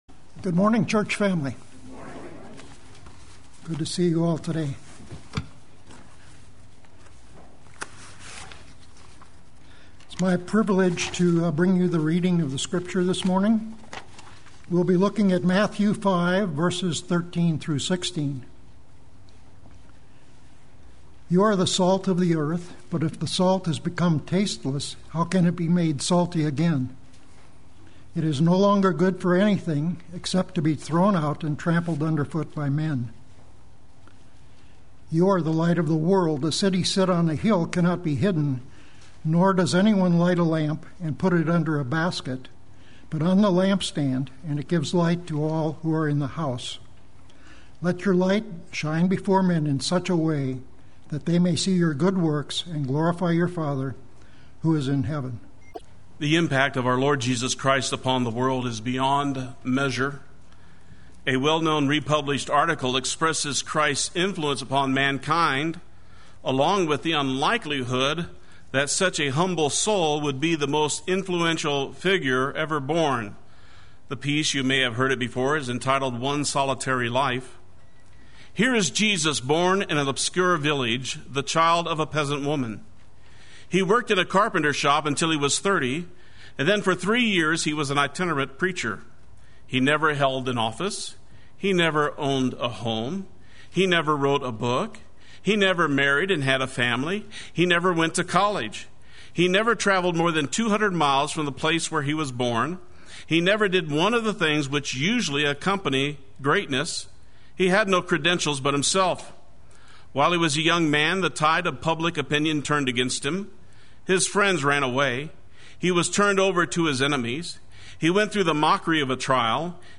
Play Sermon Get HCF Teaching Automatically.
Glorify Your Father Sunday Worship